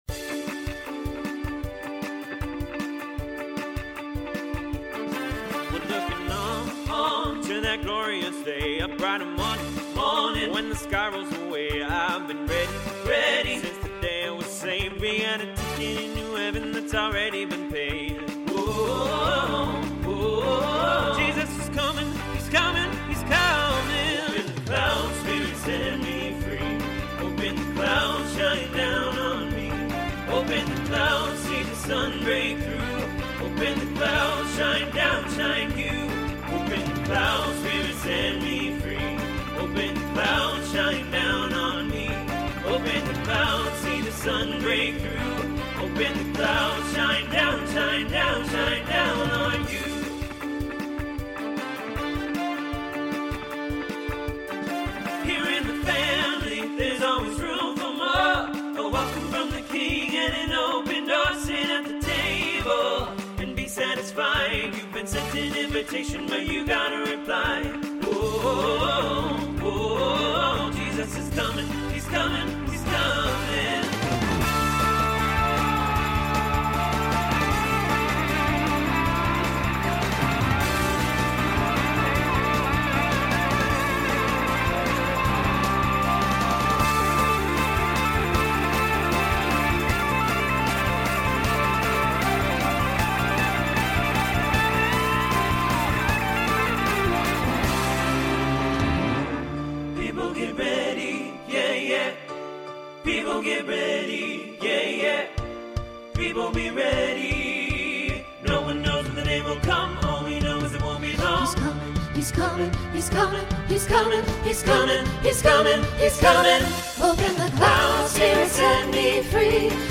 Starts TTB, ends SATB.
Mixed
Pop/Dance